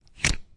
电源插头
描述：插头安装在插座中，开关打开。
Tag: 切换 OWI 开关 堵塞式 变型中 接通 墙壁 功率 插头